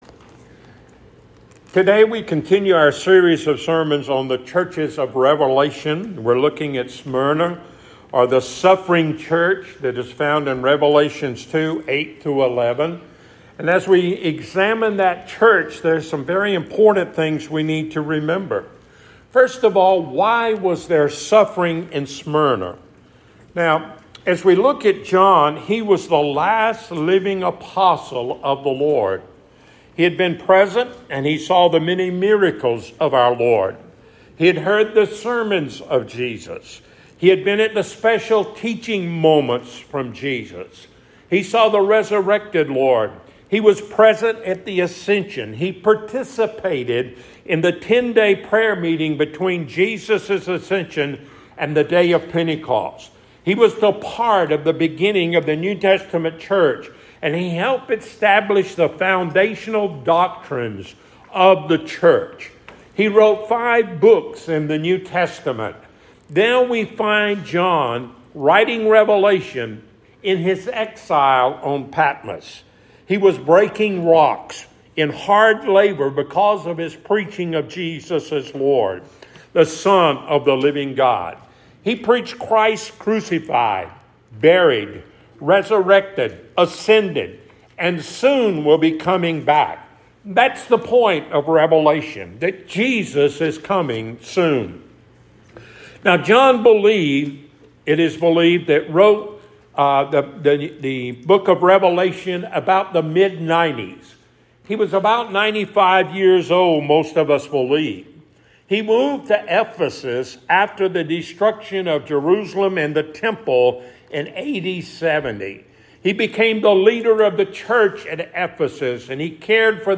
7 Churches in Revelation Passage: Rev 2:8-11 Service Type: Sunday Morning « The woman with the open heart.